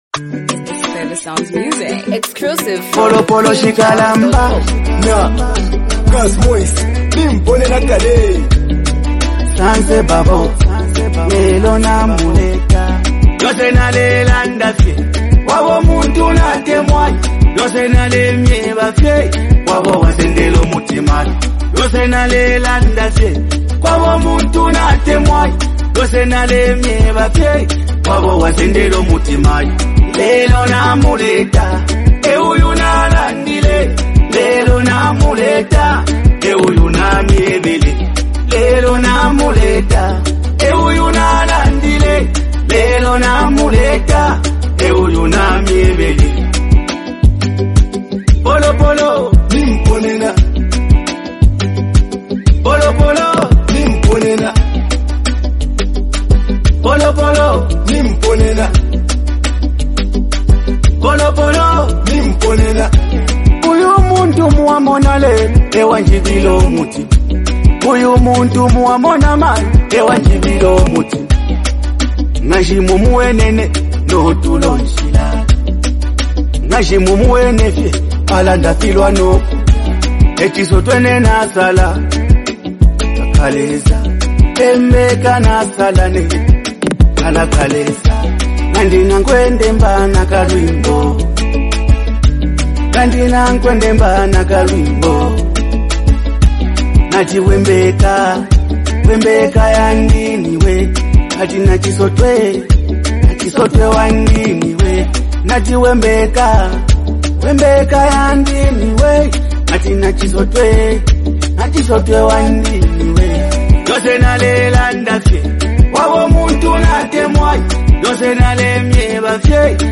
emotional love song
Through melodic vocals and heartfelt lyrics